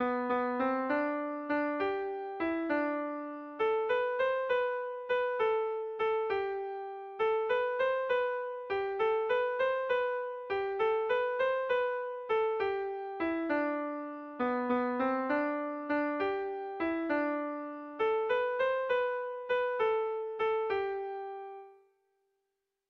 Haurrentzakoa
Doinuaren amaiera 1.puntu-doinua errepikatuz egiten da.
ABD